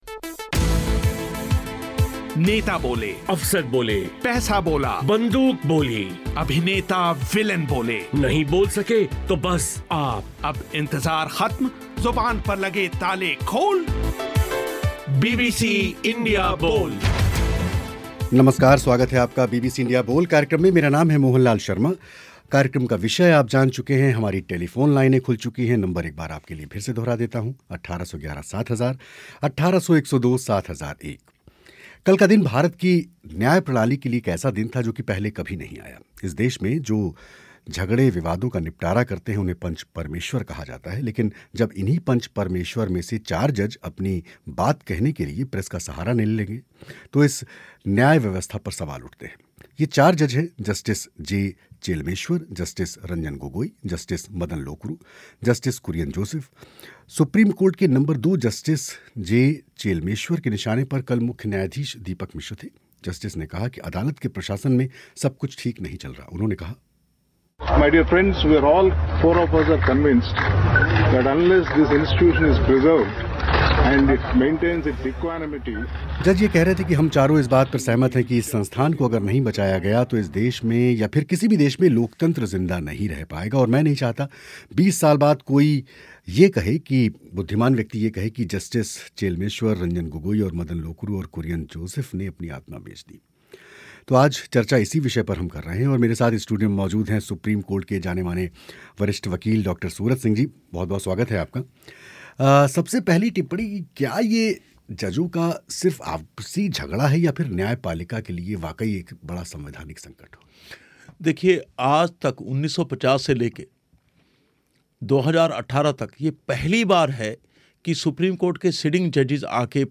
कार्यक्रम में इसी विषय पर हुई चर्चा.